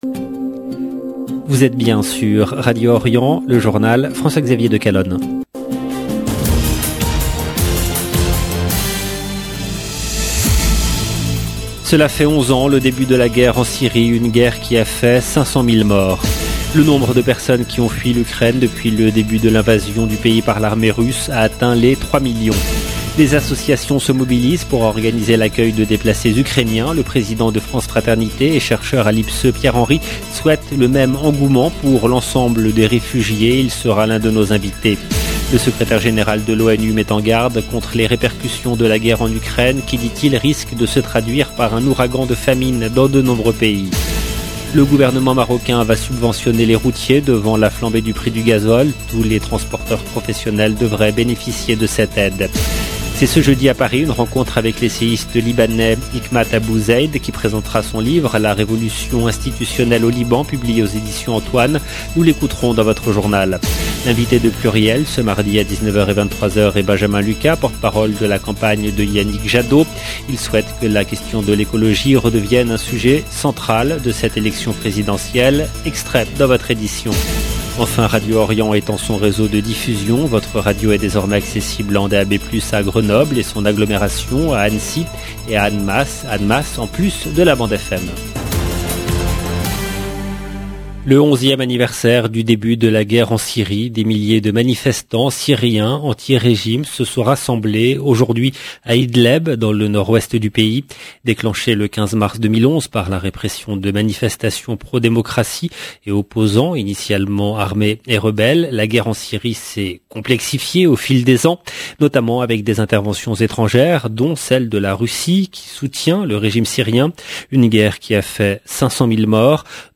LE JOURNAL EN LANGUE FRANCAISE DU SOIR DU 15/03/22